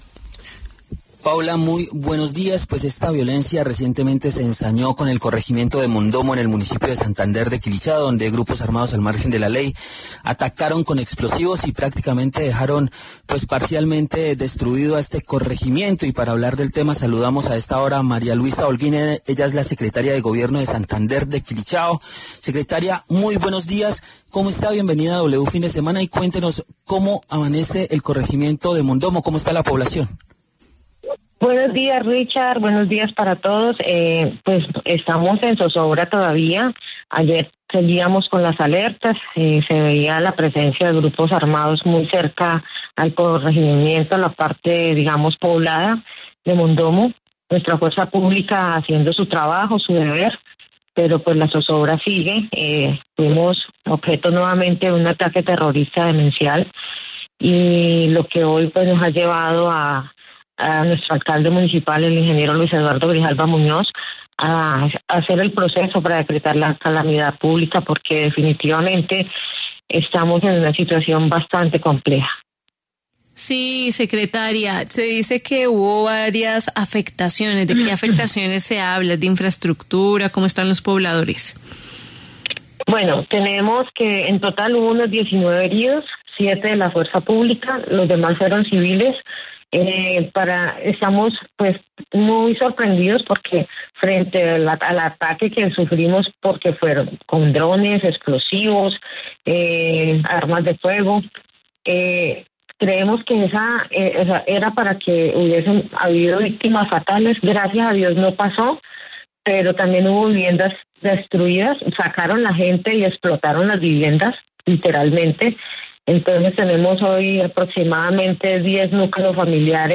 Maria Luisa Holguín pasó por los micrófonos de La W para denunciar la grave crisis que atraviesa su localidad tras devastador ataque de las disidencias.